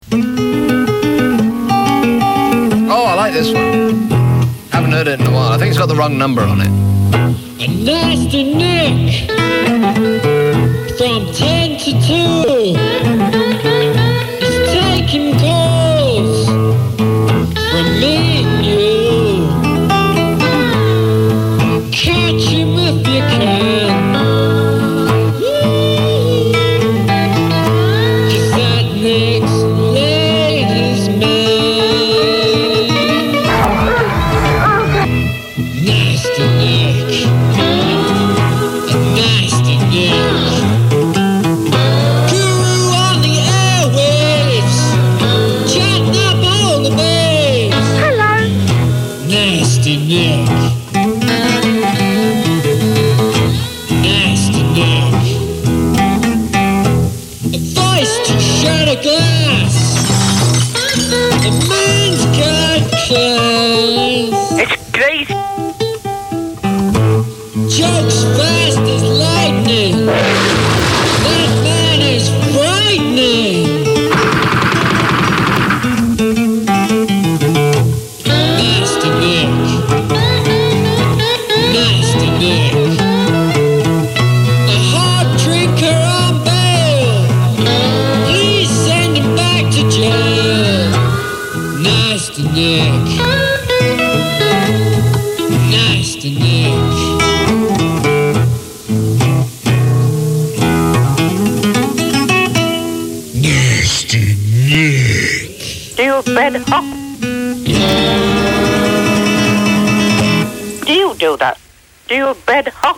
These were made by fans of his show.